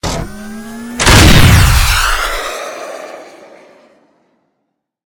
battlesuit_rocket.ogg